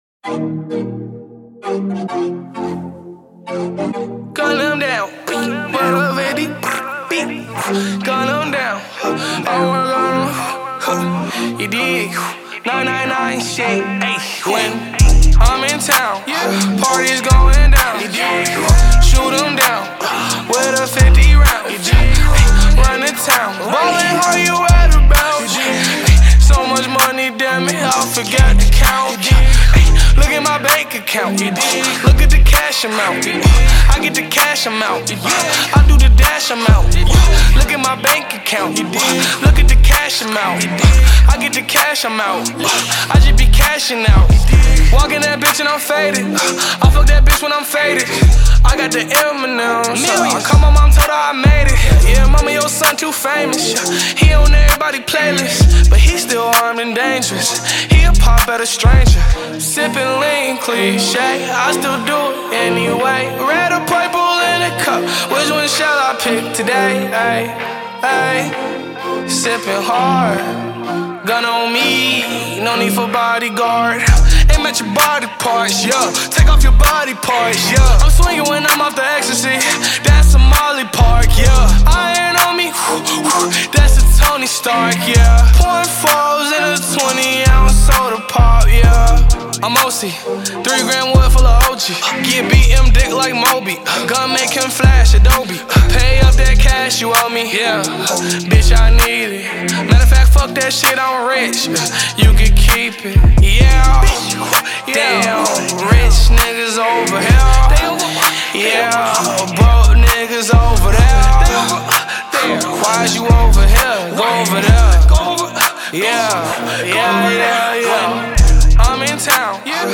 Genre: Rap / hip hop